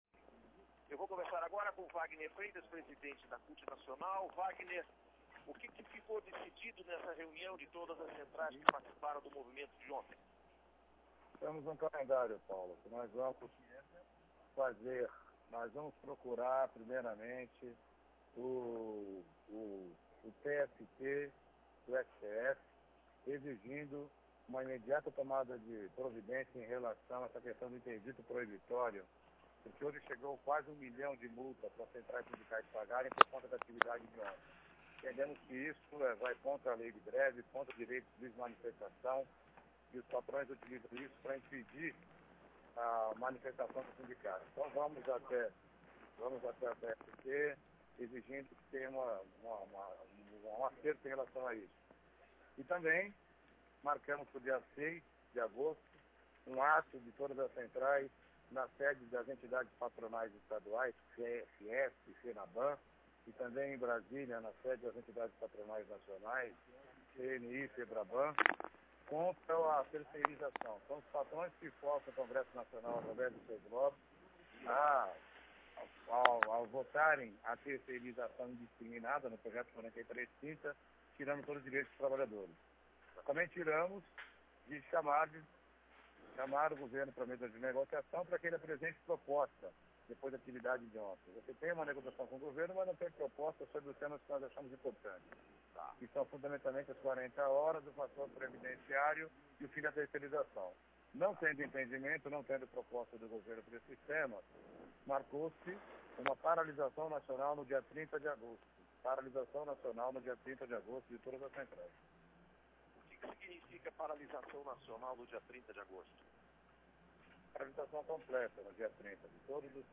Acompanhe a integra da entrevista em áudio e texto: 1- PHA